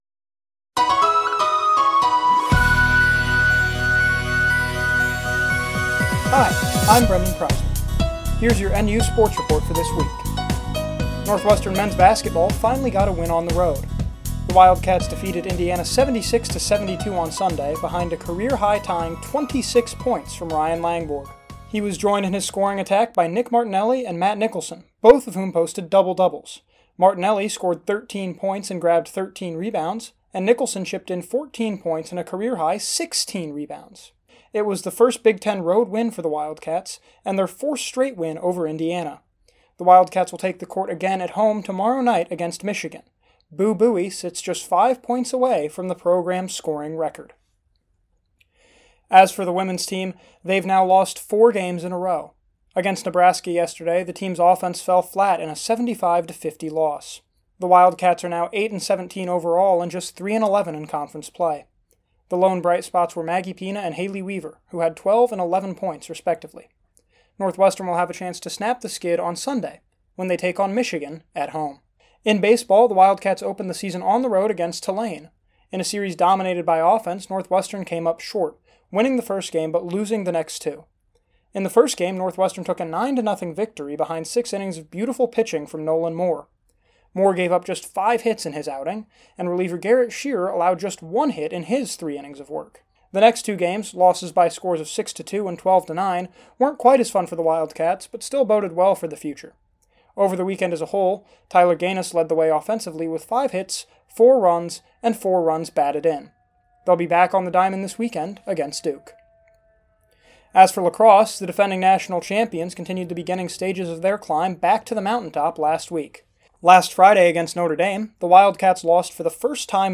NU Sports Report